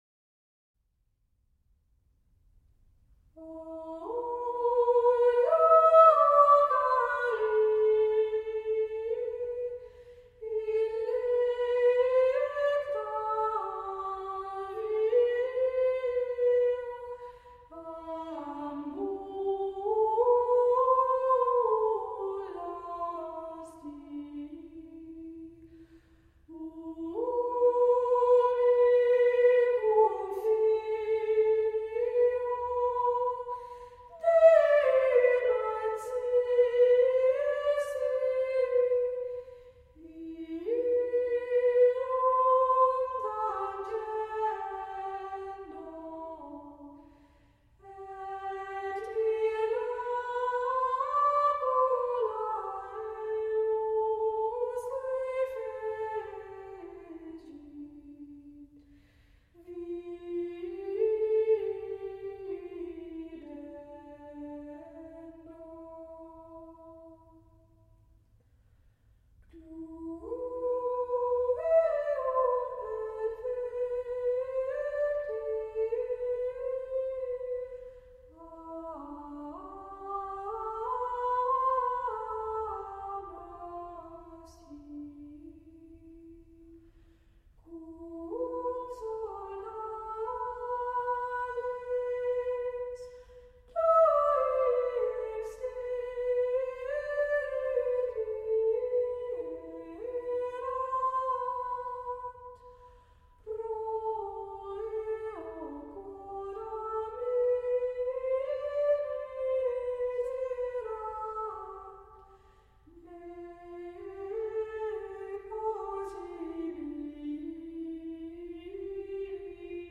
Choir
A musical ensemble of singers.